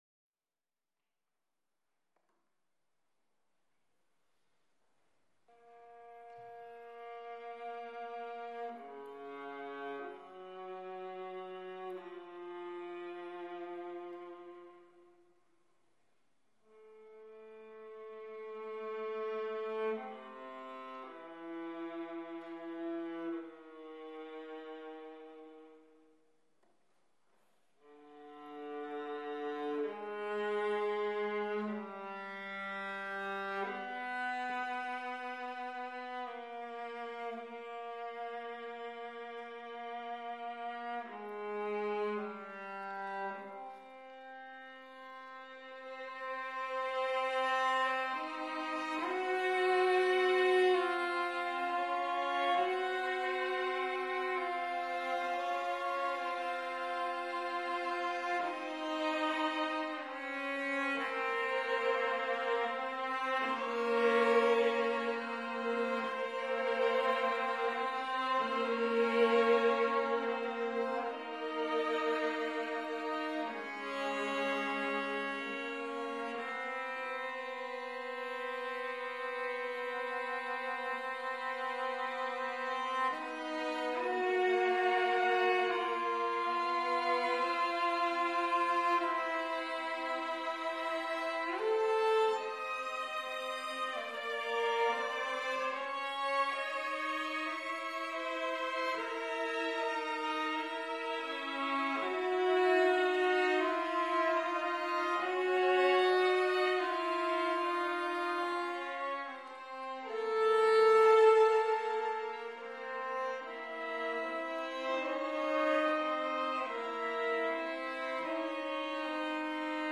Kreutzer Quartet
Live at Michael Tippett Centre, Bath